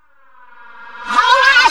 VOXREVERS1-R.wav